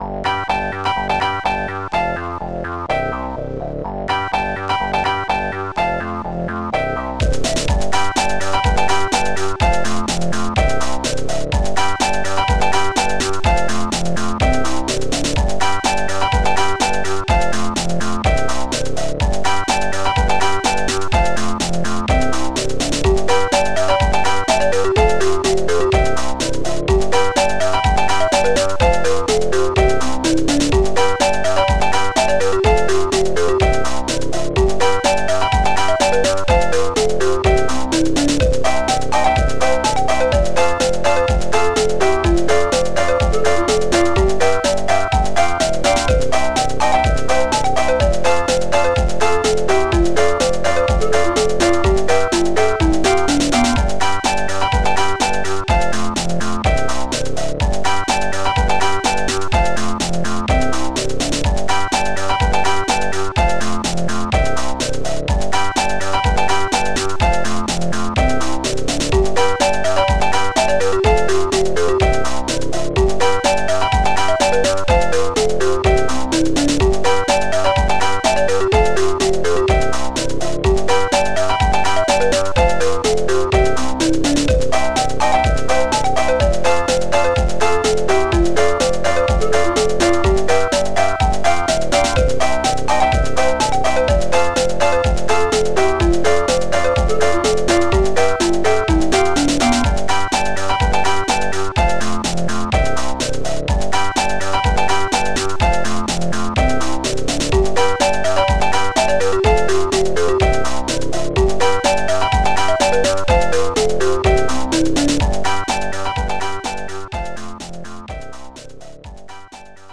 Protracker Module
flute